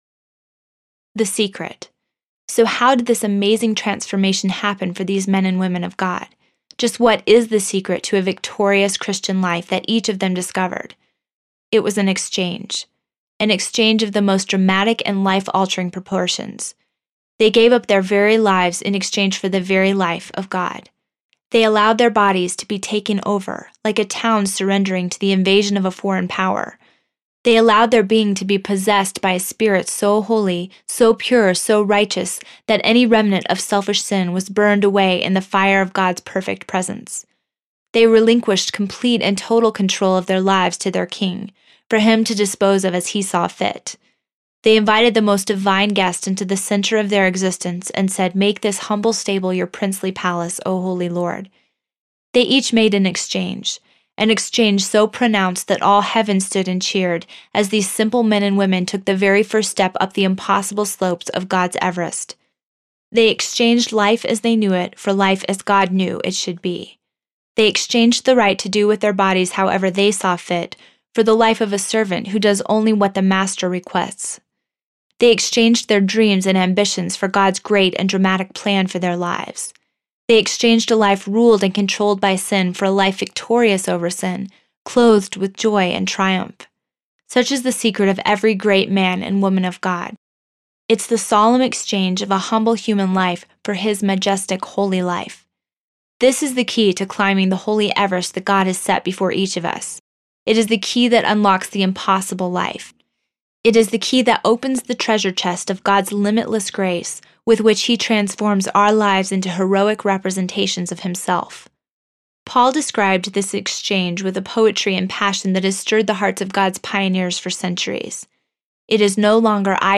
When God Writes Your Life Story Audiobook